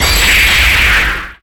The "finish hit" sound effect as heard in Super Smash Bros. Ultimate, a video game published by Nintendo.
FinishZoom.mp3